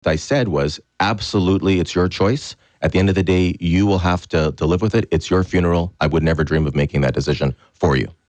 Mitch-Panciuk-1.mp3